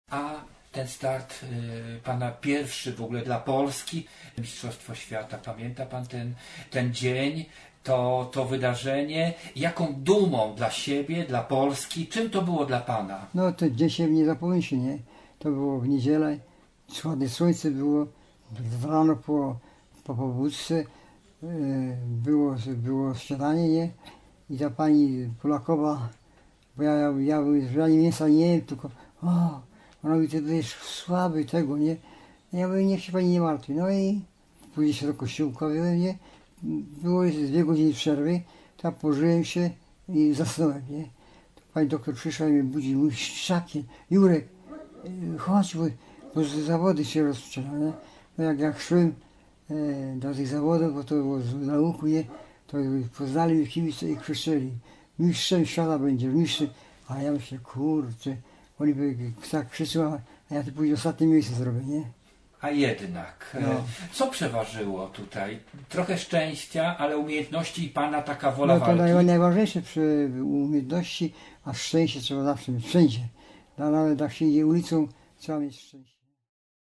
Jerzy Szczakiel - ostatni wywiad (fragment)